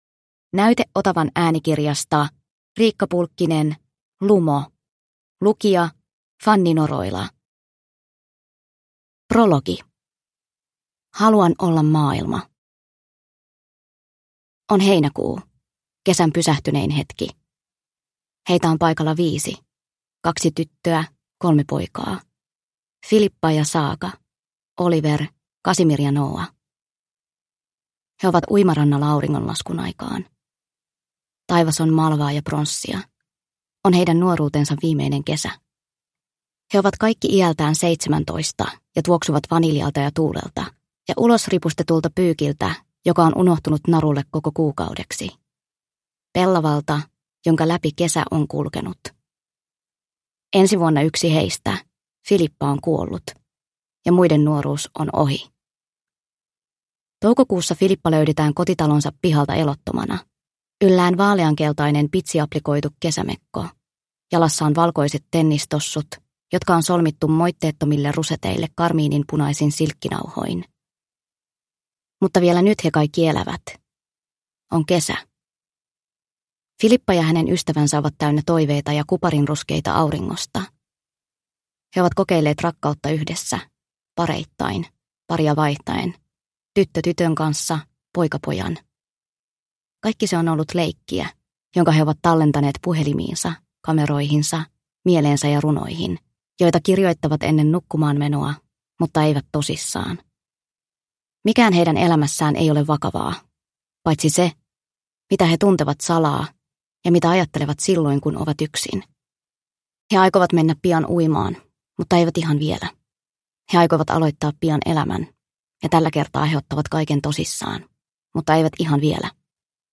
Lumo – Ljudbok – Laddas ner